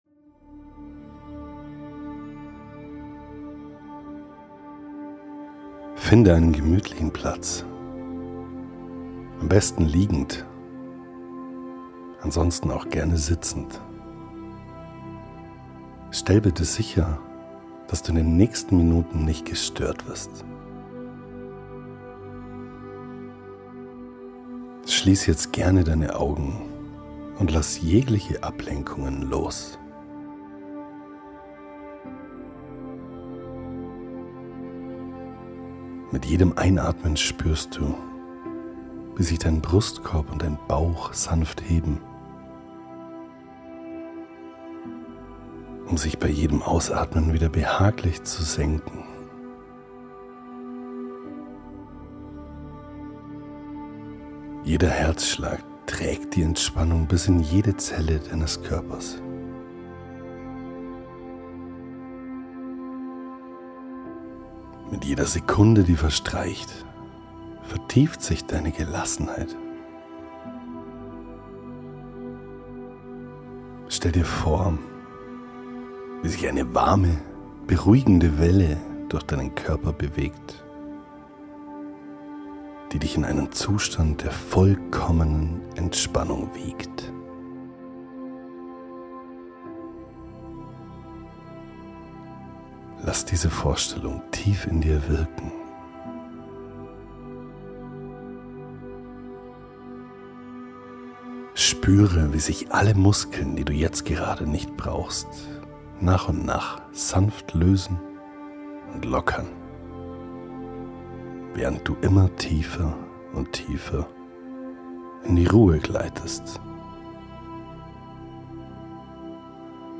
Diese Meditation führt dich sanft in die Kraft des (Selbst-)Respekts und unterstützt dich darin, deinen inneren Wert zu erkennen und zu stärken.